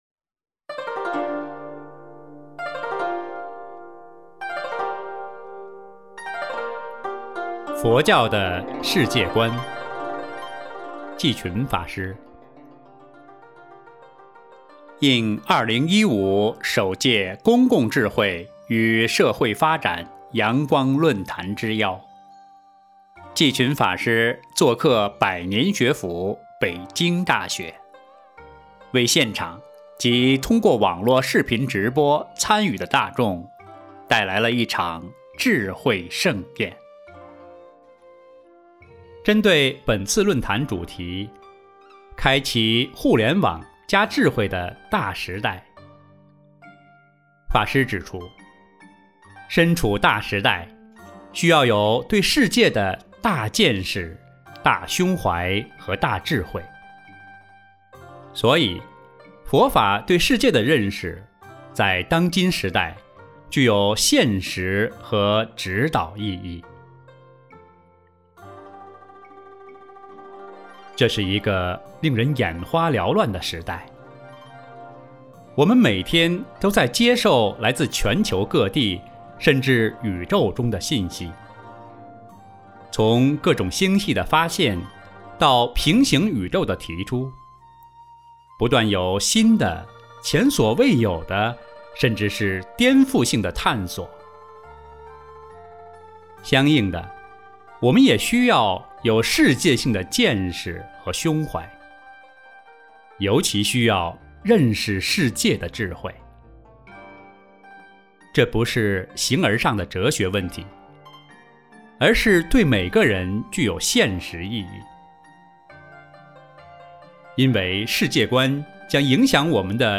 有声书